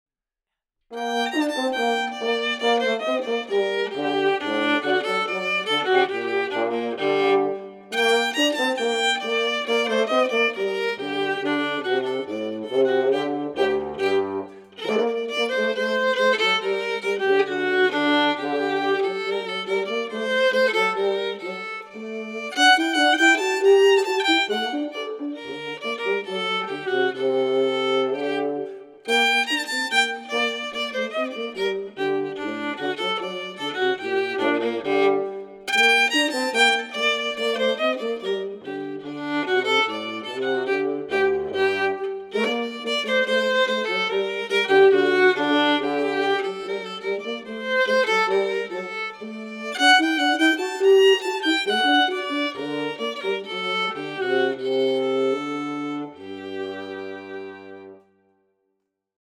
Abbracci Libero - Hidden Meaning Music - French Horn Jazz
violin, accordion, viola, guitar, & vocals
French horn, electric bass